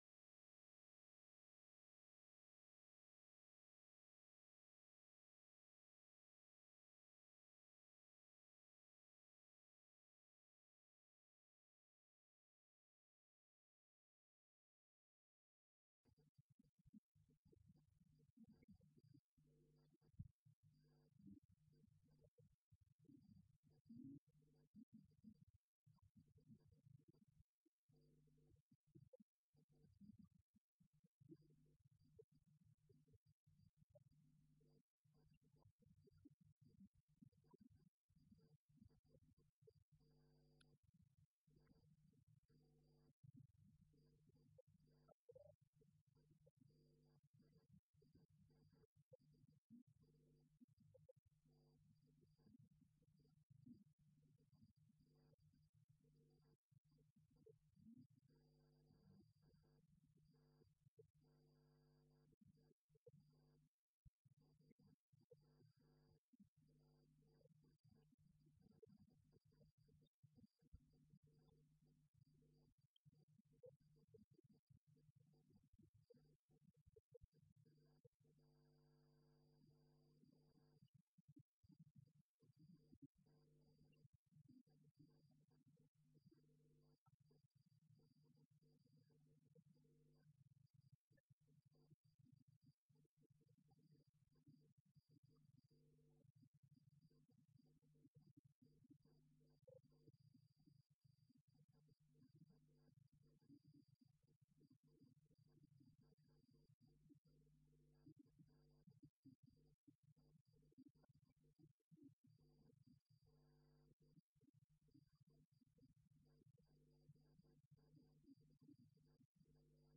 Session Traduction, langage et pensée philosophique Colloque L’Islam et l’Occident à l’époque médiévale.